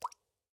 Minecraft Version Minecraft Version 1.21.5 Latest Release | Latest Snapshot 1.21.5 / assets / minecraft / sounds / block / pointed_dripstone / drip_water2.ogg Compare With Compare With Latest Release | Latest Snapshot
drip_water2.ogg